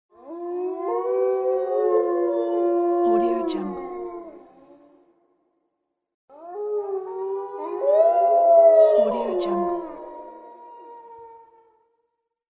Wolf Bold Howling Téléchargement d'Effet Sonore
Wolf Bold Howling Bouton sonore